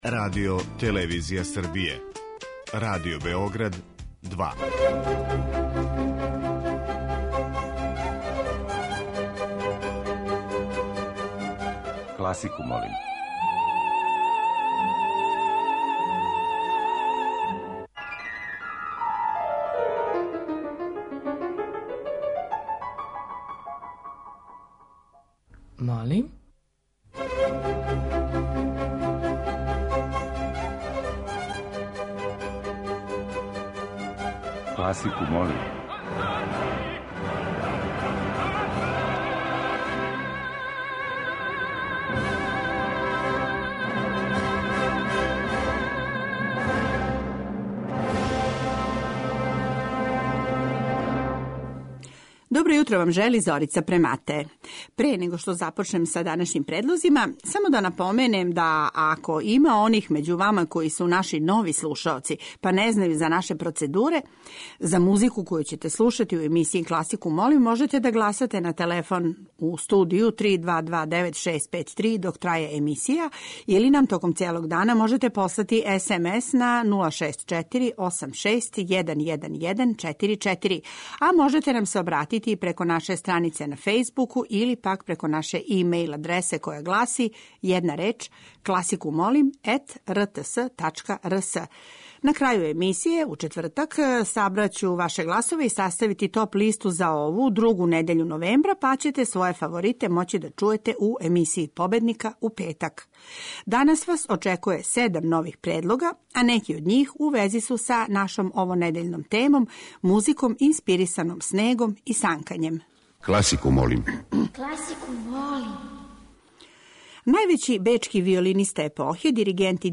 Између осталих, чућете неколико композиција посвећених санкању или снежним пејзажима, а из пера стваралаца разних стилова и епоха: Дилијуса, Дебисија, Свиридова, Прокофјева и Листа.